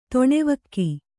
♪ toṇevakki